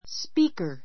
spíːkə r